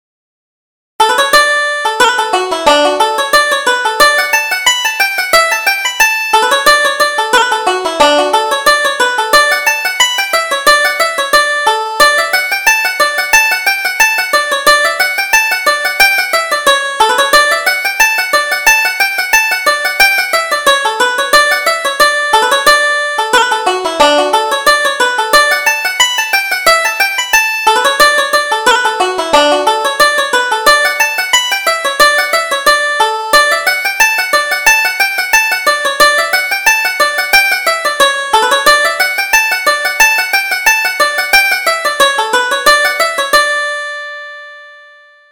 Reel: Sauntering in the Lane